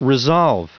Prononciation du mot resolve en anglais (fichier audio)
Prononciation du mot : resolve